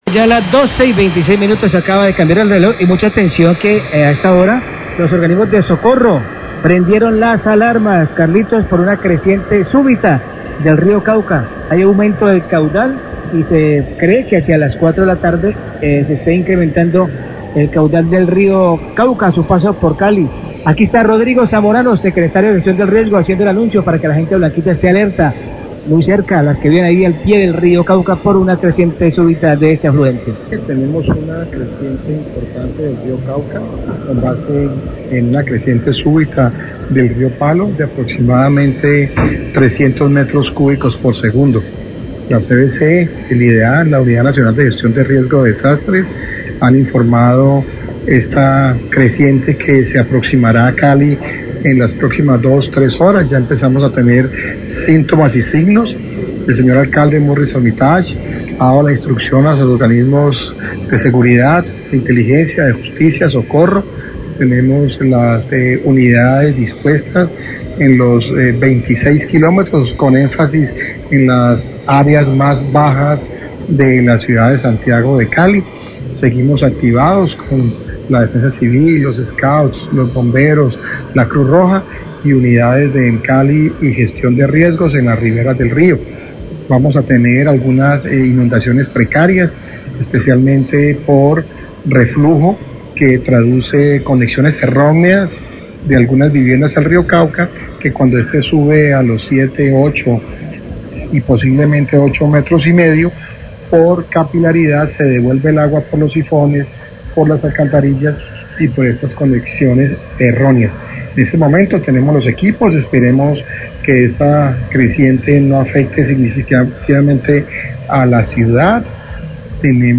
El secretario de Gestión de Riesgo, Rodrigo Zamorano, habló sobre la alerta que tienen las autoridades por una creciente en el río Cauca que se espera para la tarde de hoy.  Alcalde ha dado orden a diferentes entidades para realizar monitoreo del río.